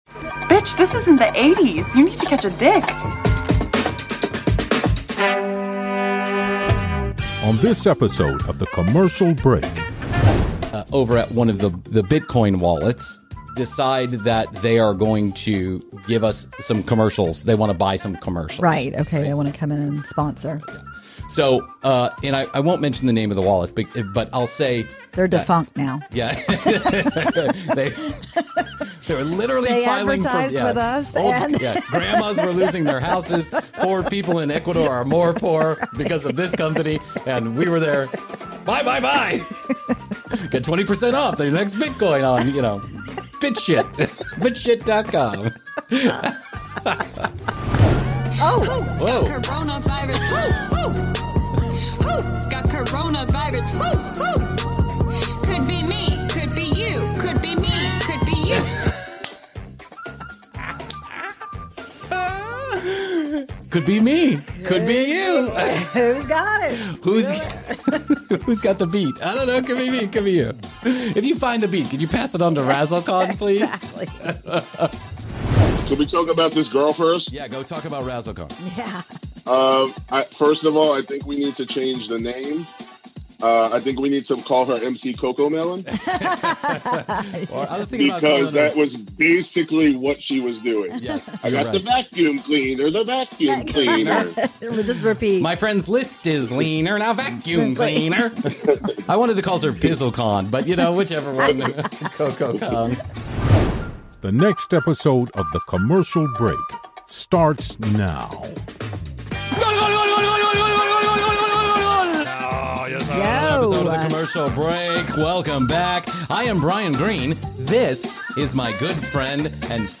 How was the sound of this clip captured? He calls in to it live.